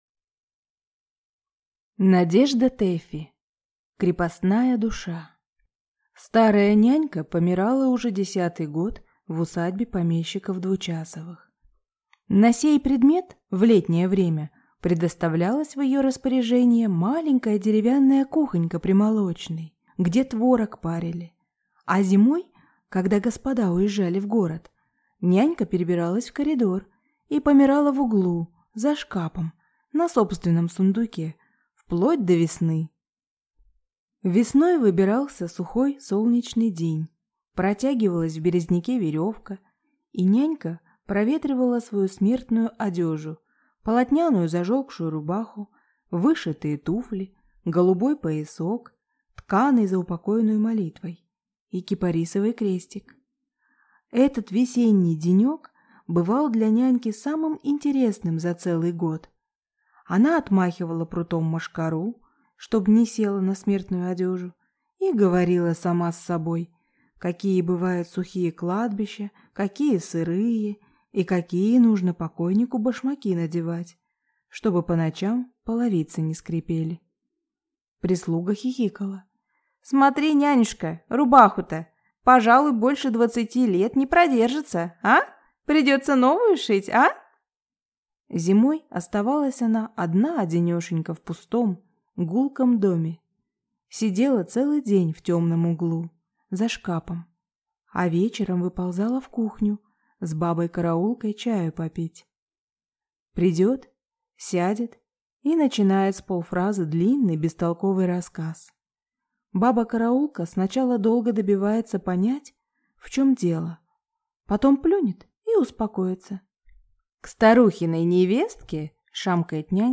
Aудиокнига Крепостная душа Автор Надежда Тэффи